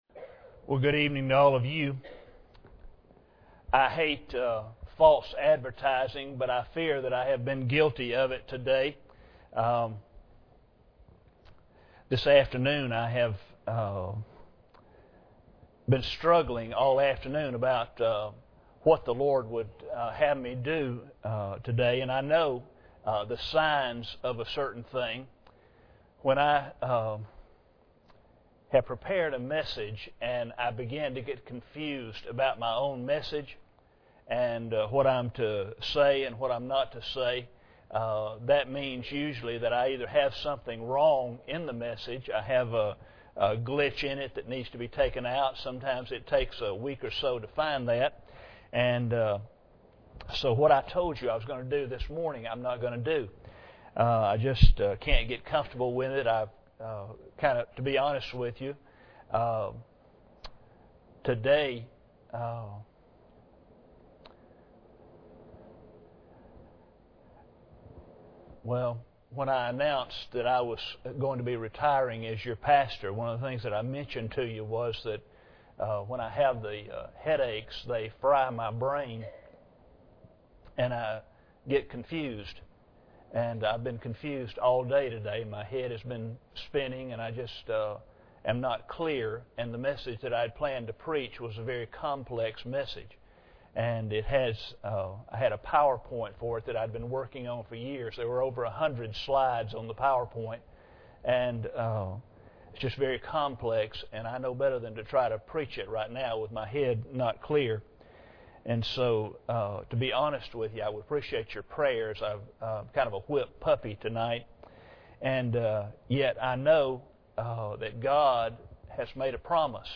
General Passage: Daniel 6:3-28 Service Type: Sunday Evening Bible Text